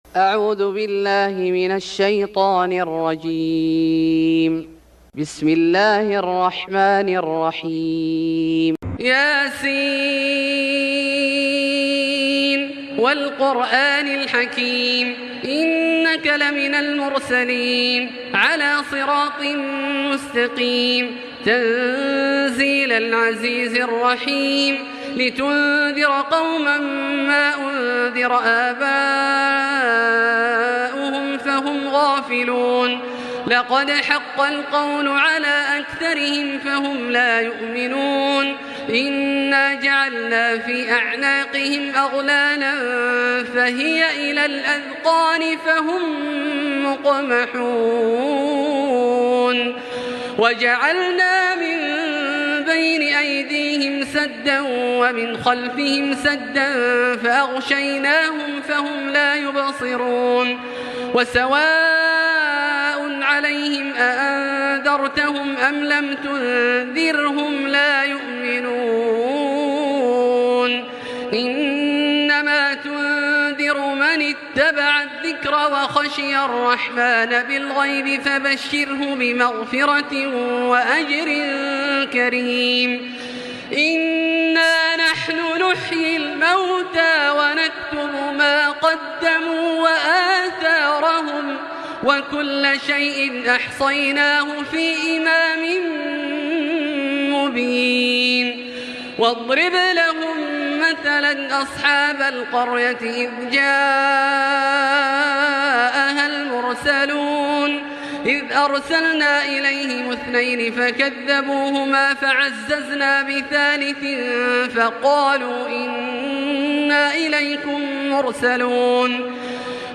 سورة يس Surat Ya Sin > مصحف الشيخ عبدالله الجهني من الحرم المكي > المصحف - تلاوات الحرمين